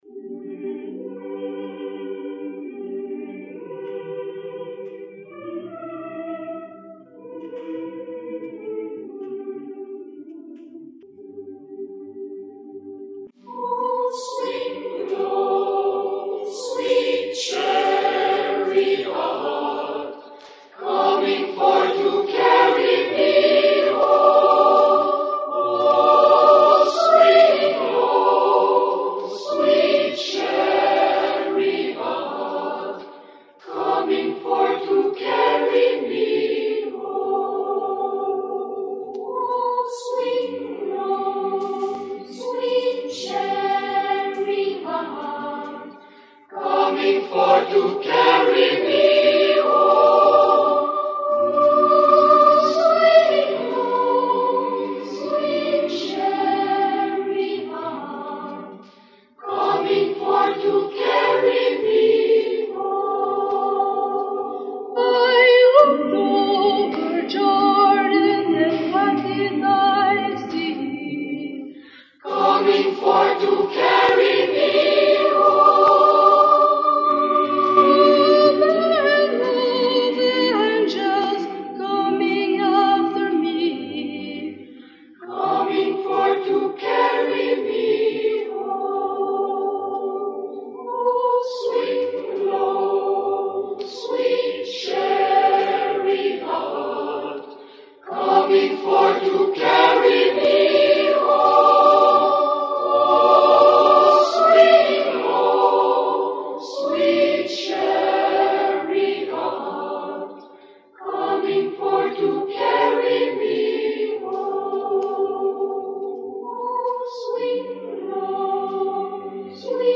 Negro Spiritual – harm.